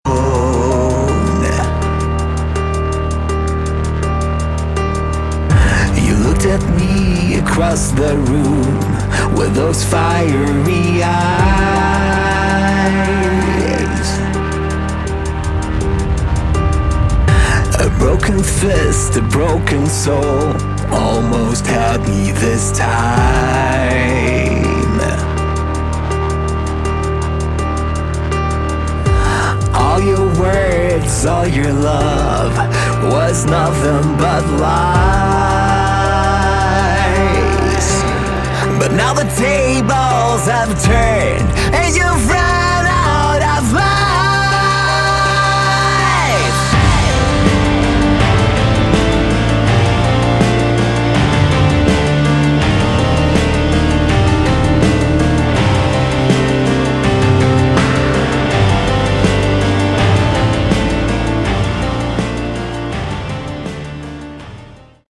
Category: Melodic Metal
Guitars, Vocals
Bass
Drums